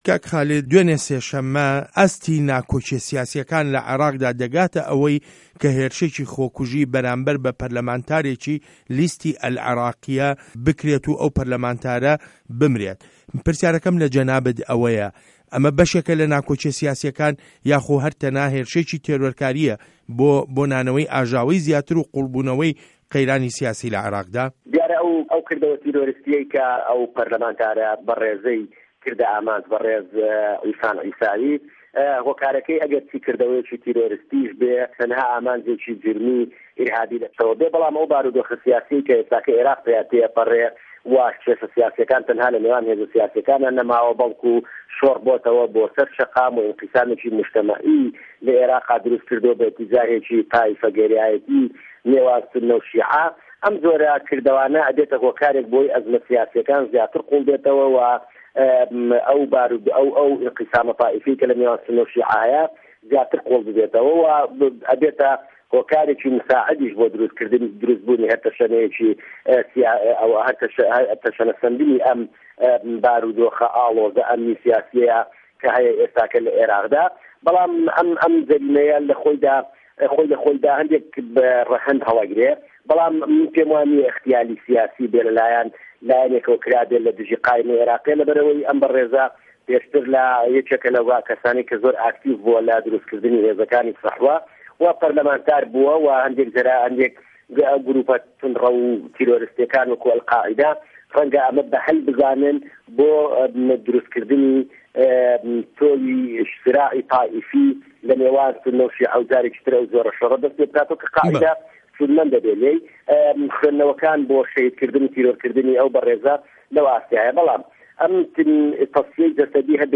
وتووێژ له‌گه‌ڵ خالید شوانی